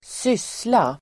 Uttal: [²s'ys:la]